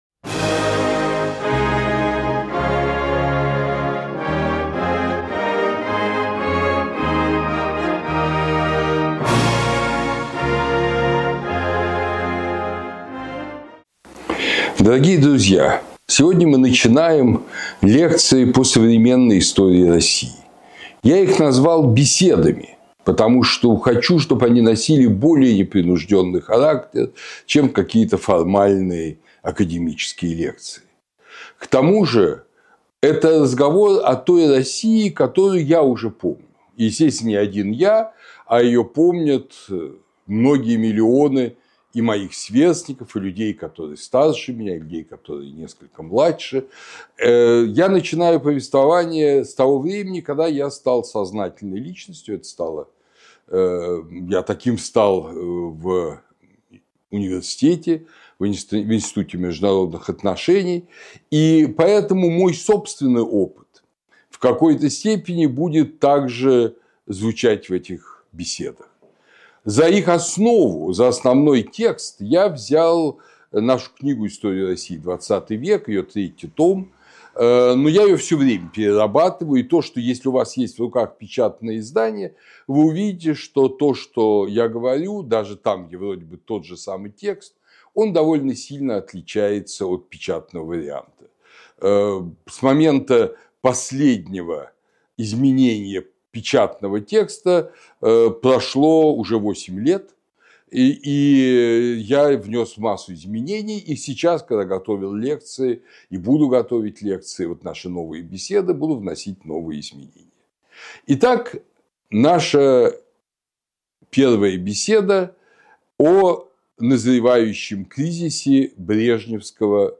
Курс Андрея Зубова по новейшей истории России